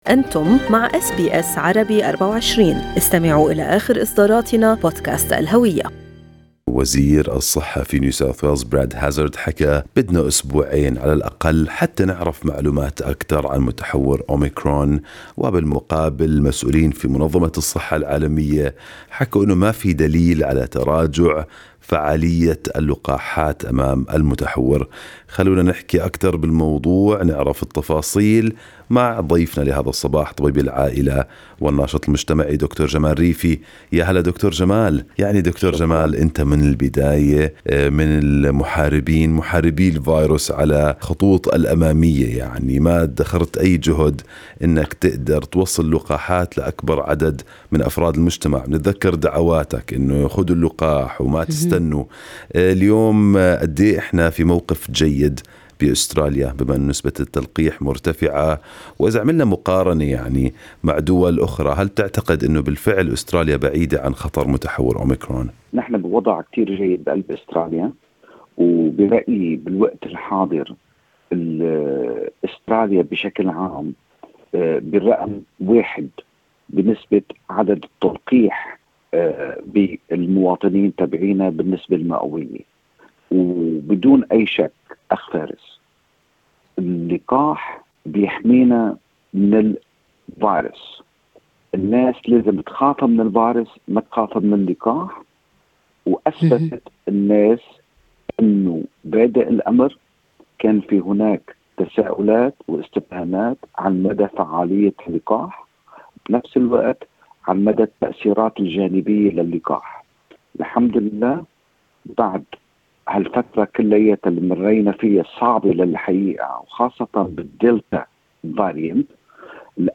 وفي حديث لأس بي أس عربي24